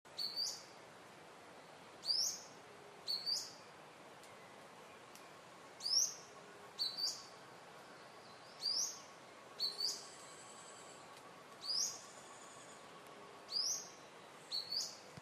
Grey-bellied Spinetail (Synallaxis cinerascens)
Life Stage: Adult
Location or protected area: Campo Ramón
Condition: Wild
Canto-8.mp3